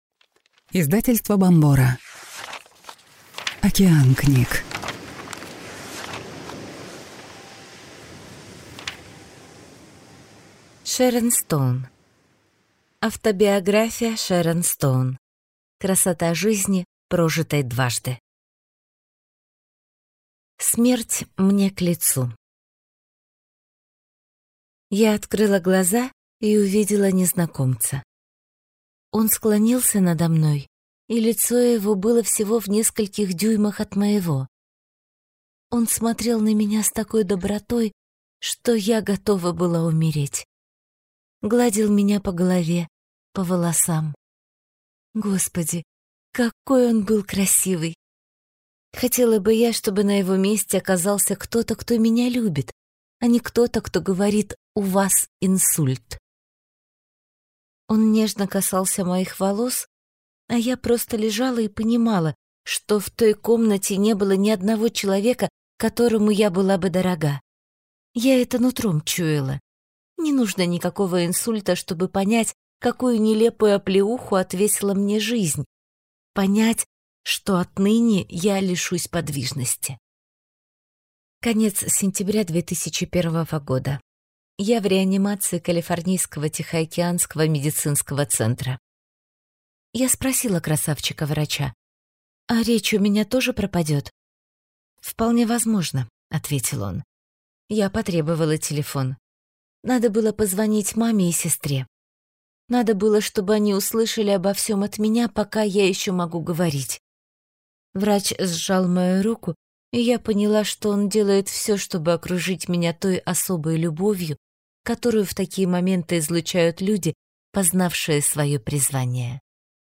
Аудиокнига Автобиография Шэрон Стоун. Красота жизни, прожитой дважды | Библиотека аудиокниг